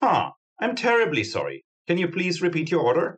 CharacterVoicelines
Ulrich_Terribly_Sorry_1.wav